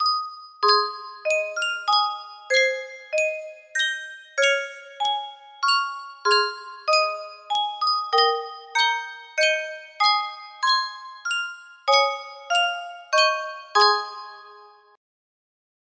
Sankyo Music Box - The Rose of Tralee 4X music box melody
Full range 60